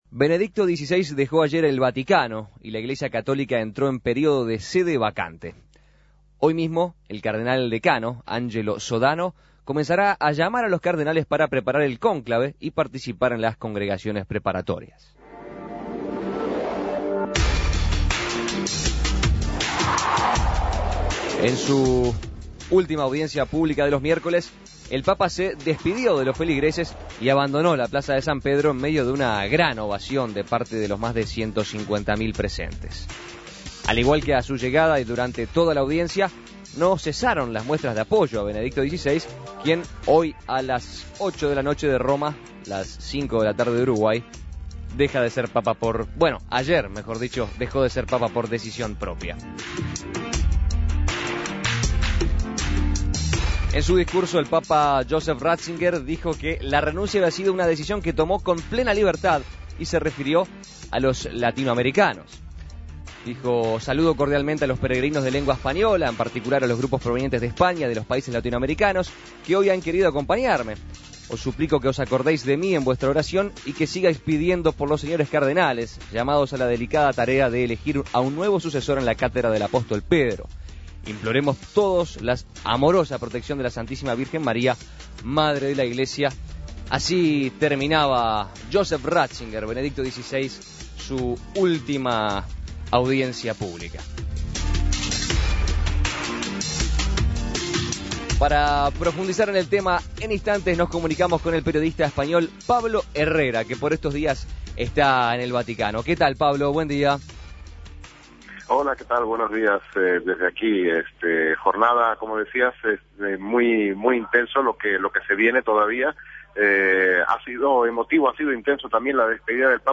periodista español, desde el Vaticano.